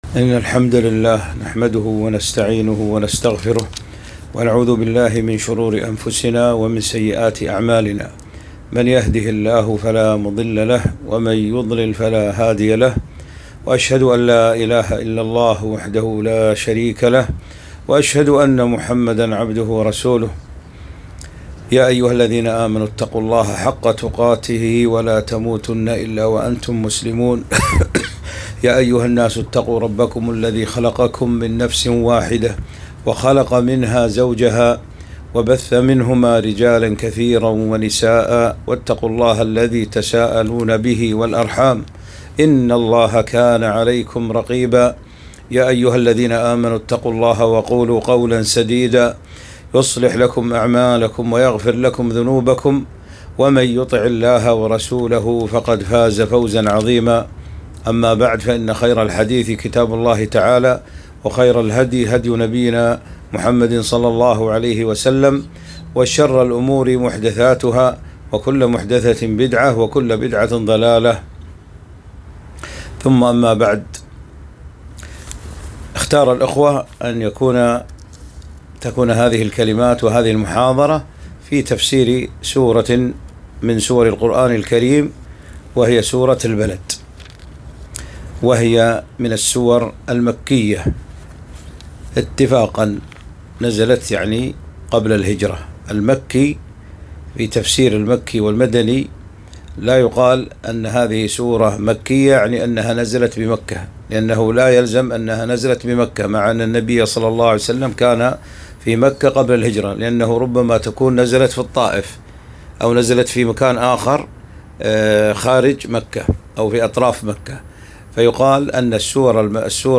محاضرة - نتقاء الزُبَدْ التي حَوَتْها سورة البَلَدْ